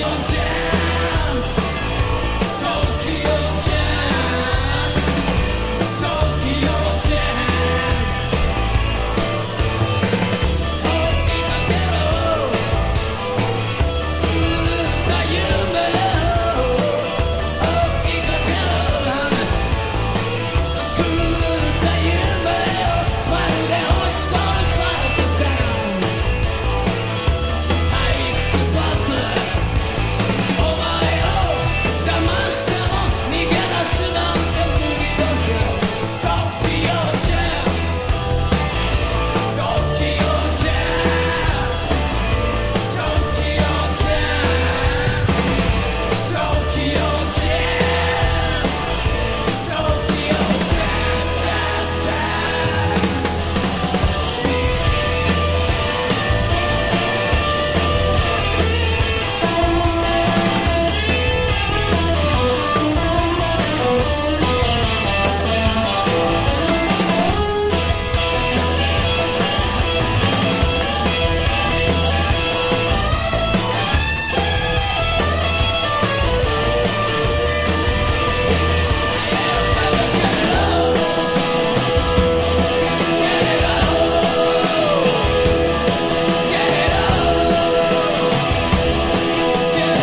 バンドとして最高潮にあった時期の粗削りな演奏がカッコいい！
# 60-80’S ROCK